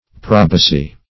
probacy - definition of probacy - synonyms, pronunciation, spelling from Free Dictionary Search Result for " probacy" : The Collaborative International Dictionary of English v.0.48: Probacy \Pro"ba*cy\, n. [See Probate .]